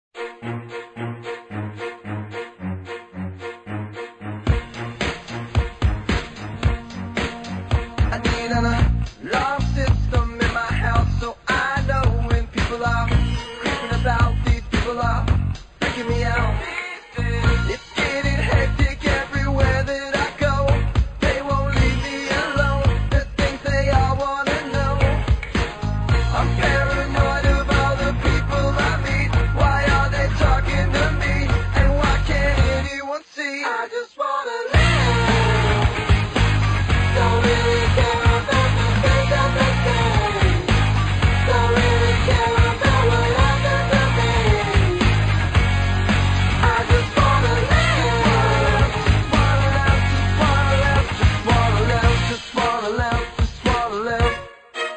Главная страница>>Скачать mp3>>Рок рингтоны